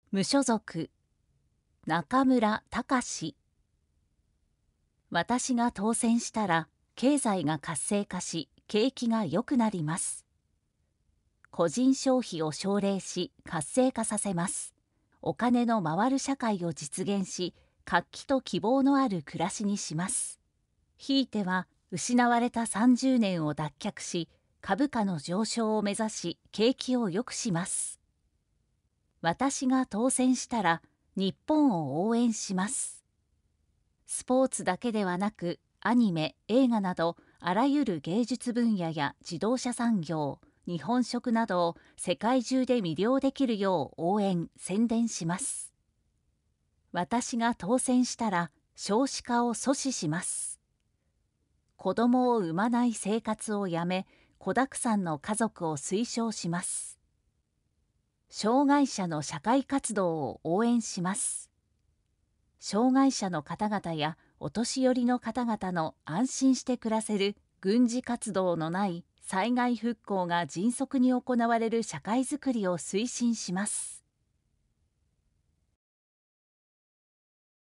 参議院議員通常選挙候補者・名簿届出政党等情報（選挙公報）（音声読み上げ用）（音声版）